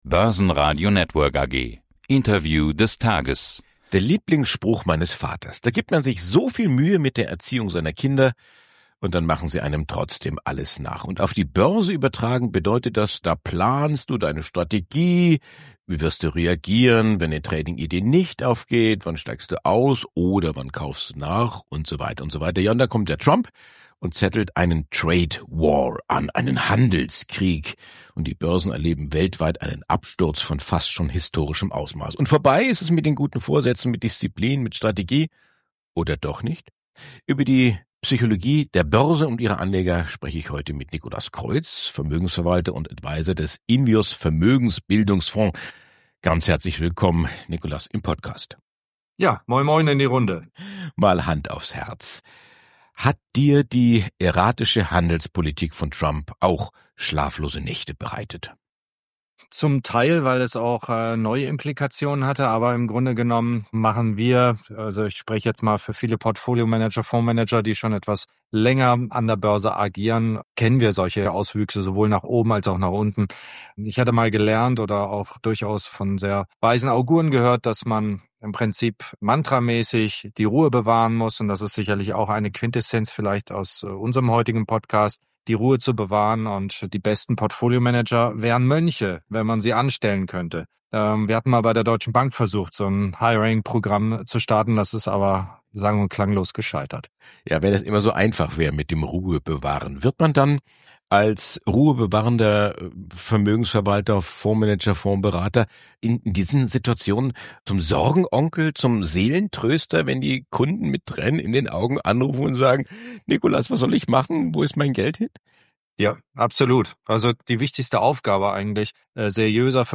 Zum Börsenradio-Interview